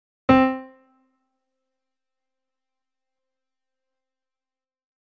音色：　サンプリング音源GM2 ピアノ１　→